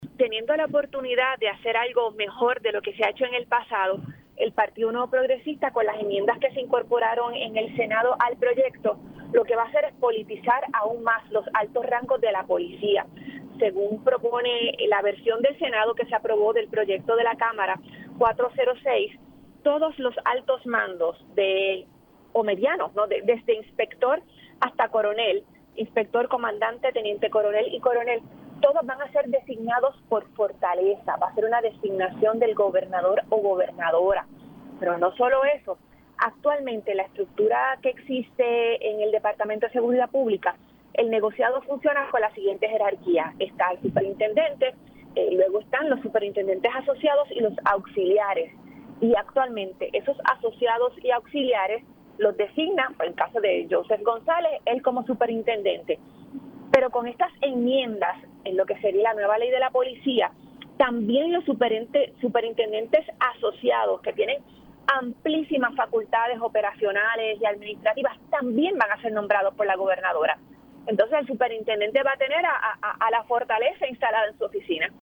315-MARIA-DE-LOURDES-SANTIAGO-SENADORA-PIP-DENUNCIA-PUESTOS-ALTOS-EN-LA-POLICIA-SERAN-NOMBRADOS-POR-GOBERNADORA.mp3